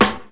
• Good Steel Snare Drum A Key 80.wav
Royality free steel snare drum tuned to the A note. Loudest frequency: 1652Hz
good-steel-snare-drum-a-key-80-nz1.wav